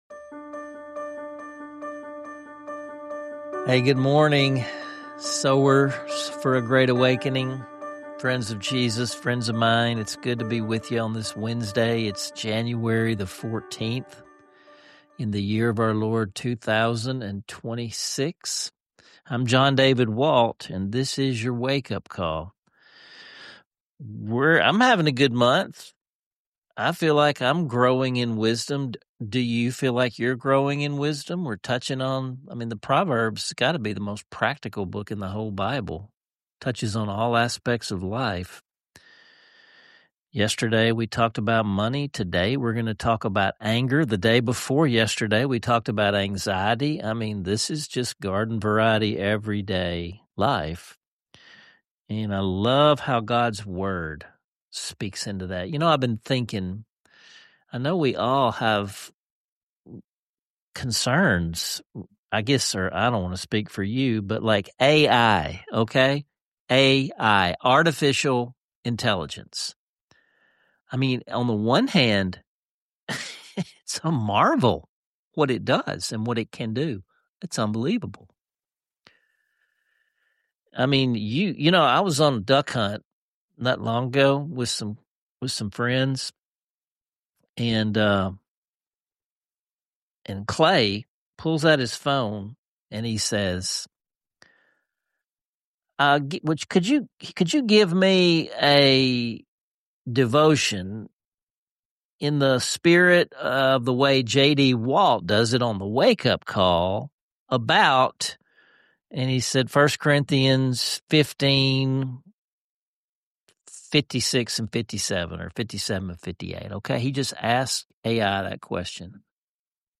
A moving rendition of the hymn “Be Still My Soul,” demonstrating how worship and stillness can bring comfort and authentic change.